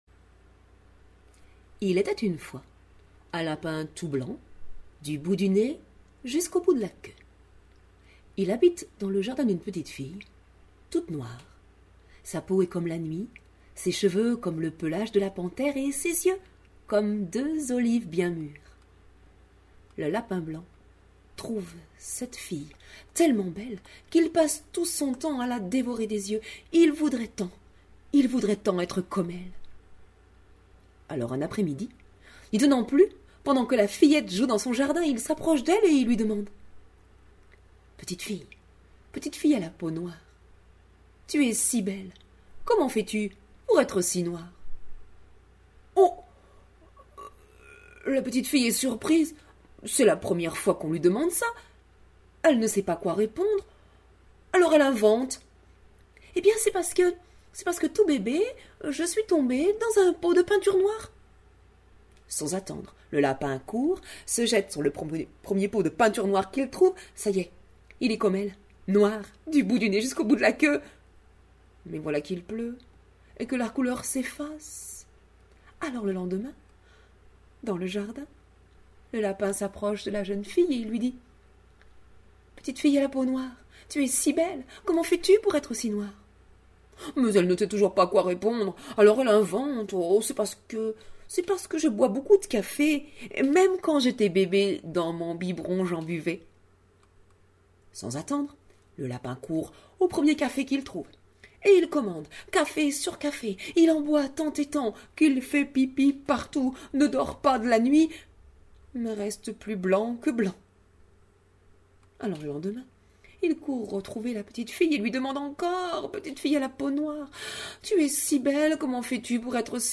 Spectacle de contes en robe-livre
parole et musique Peintures de la robe livre